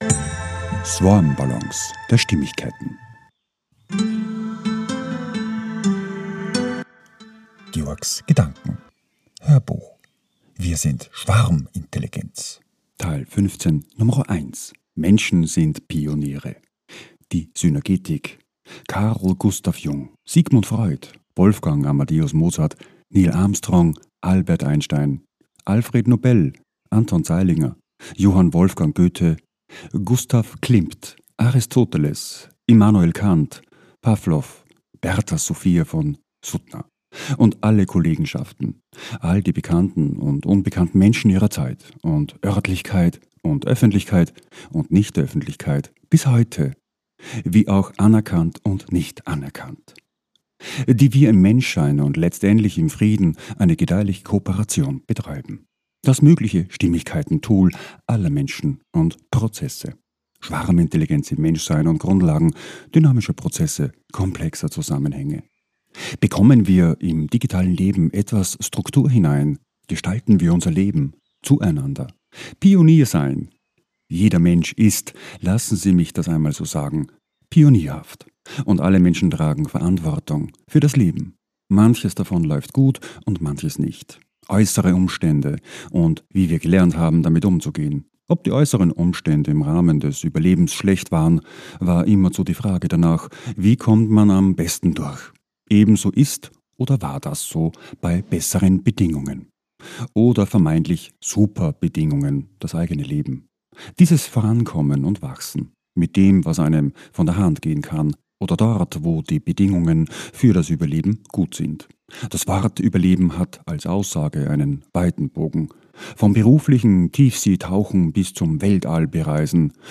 HÖRBUCH - 015.1 - WIR SIND SCHWARMINTELLIGENZ - Menschen sind Pioniere - SYNERGETIK